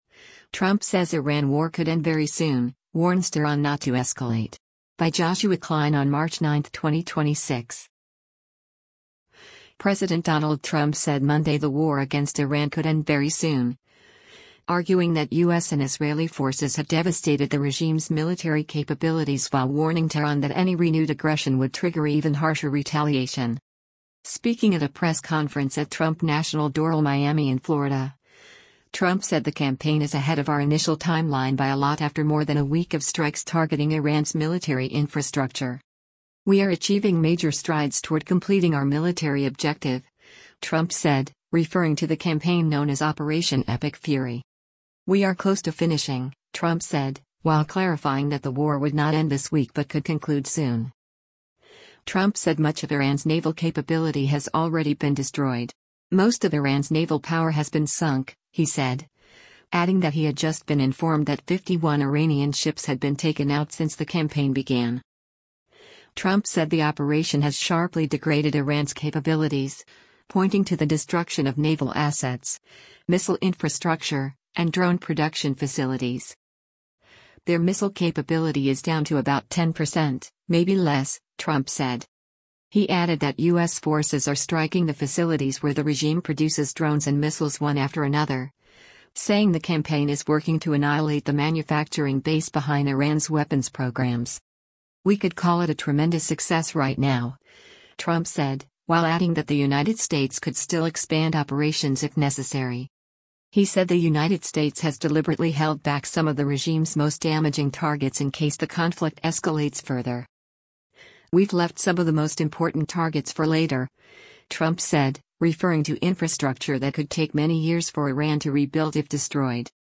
Speaking at a press conference at Trump National Doral Miami in Florida, Trump said the campaign is “ahead of our initial timeline by a lot” after more than a week of strikes targeting Iran’s military infrastructure.